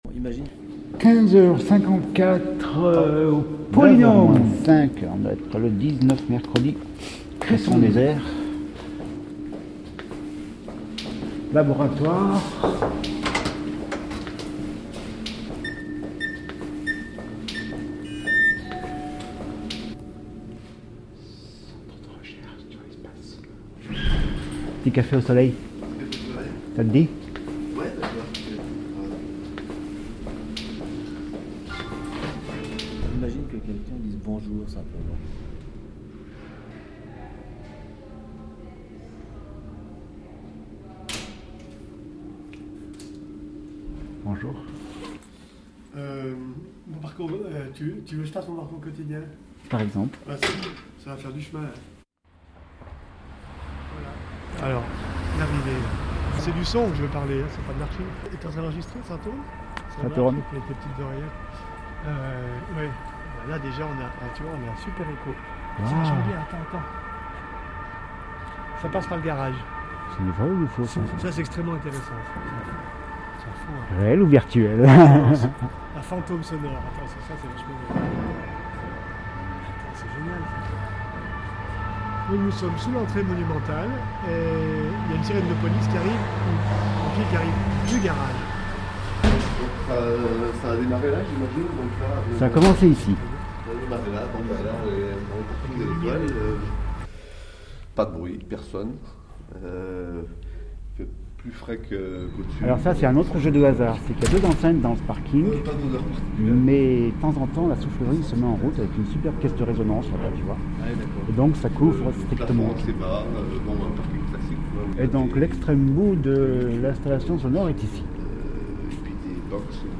Essayer de réactiver l’écoute en enregistrant qq parcours commentés